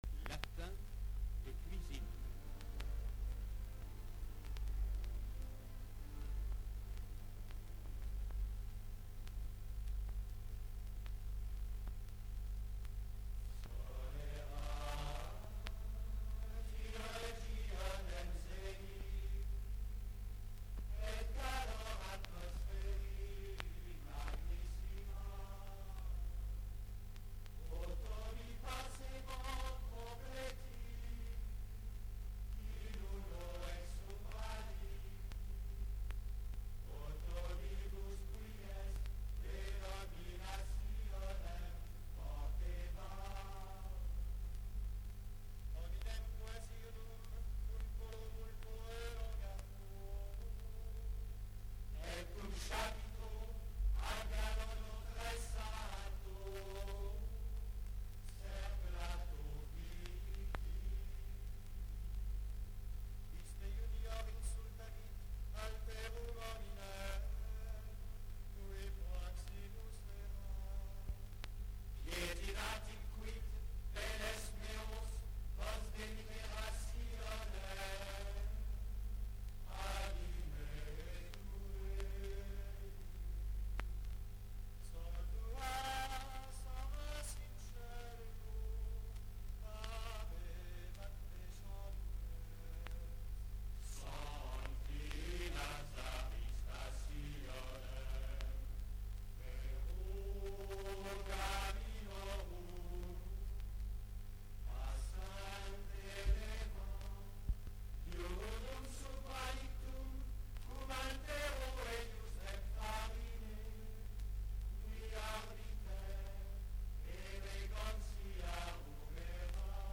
Émission de radio Sketch